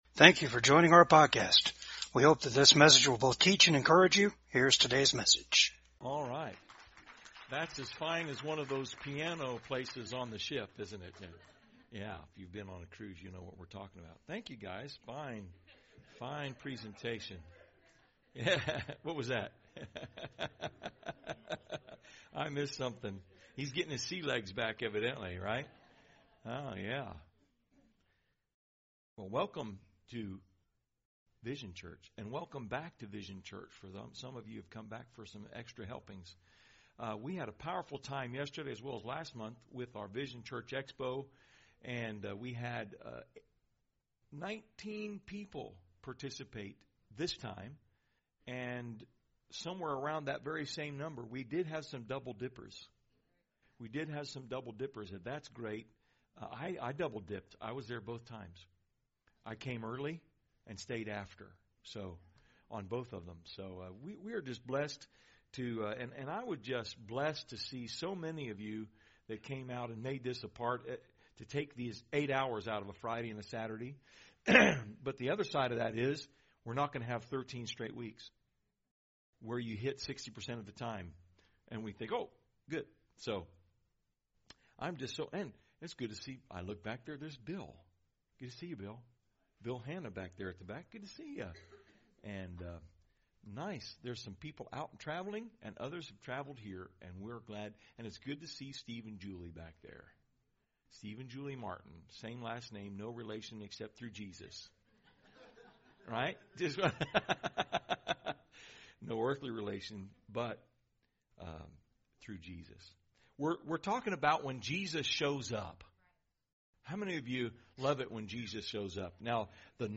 VCAG SUNDAY SERVICE